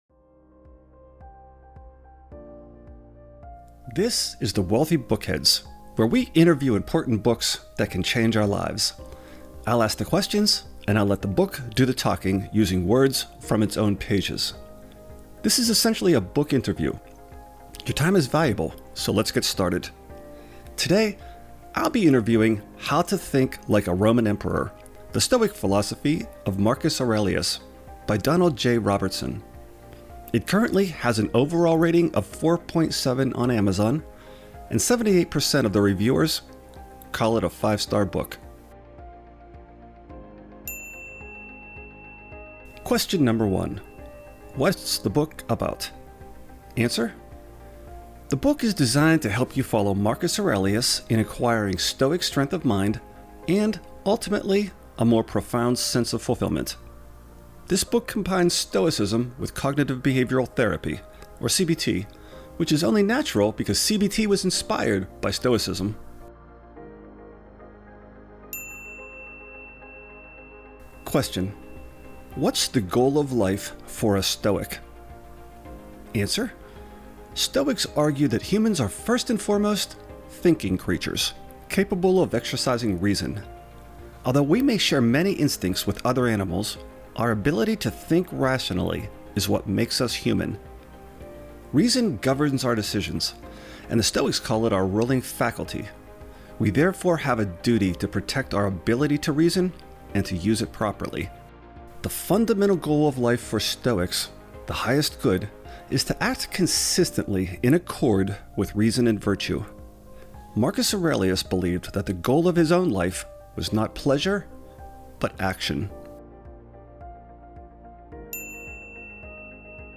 The Wealthy Bookheads Interview